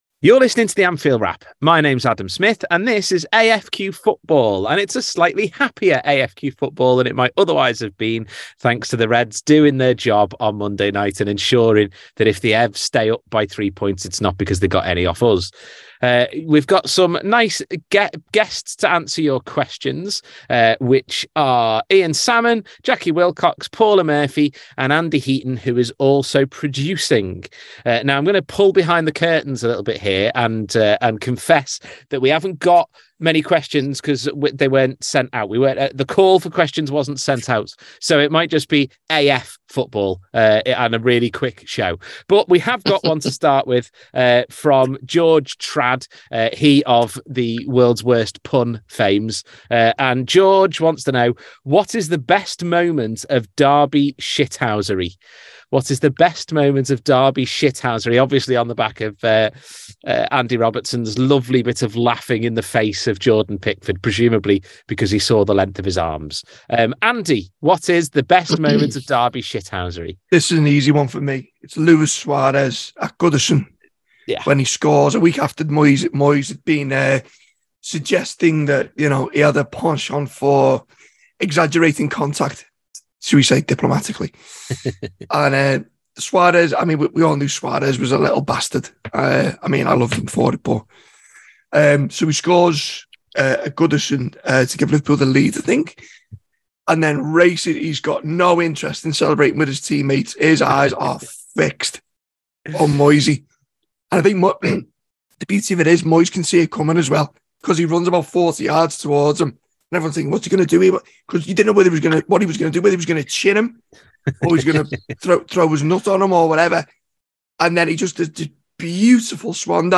Below is a clip from the show – subscribe for more on Liverpool’s midfield next season…